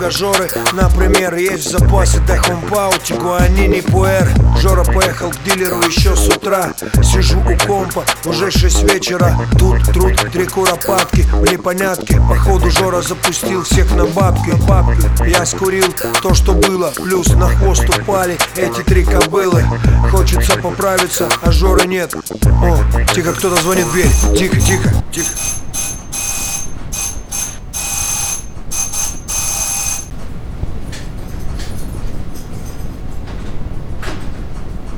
Жанр: Хип-Хоп / Рэп / Русский рэп / Русские
Hip-Hop, Rap